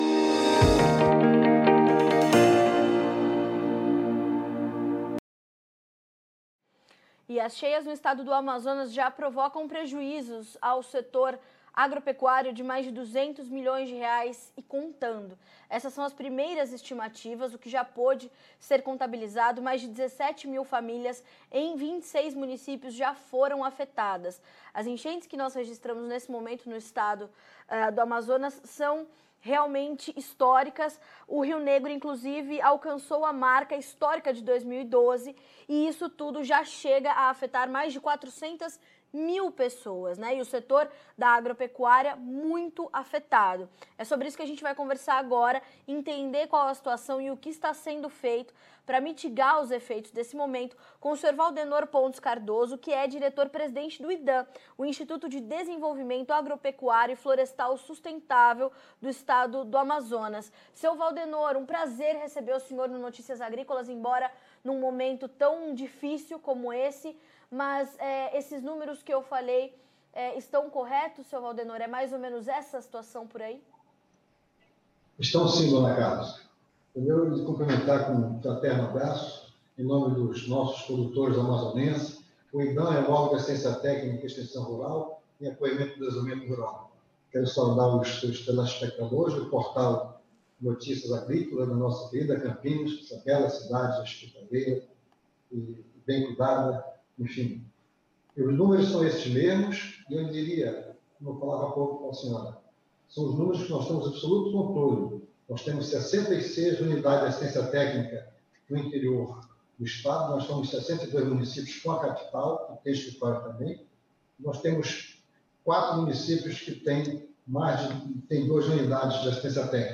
Entrevista com Valdenor Pontes Cardoso - Diretor-Presidente do IDAM sobre Chuvas Afetam Agricultores no Amazonas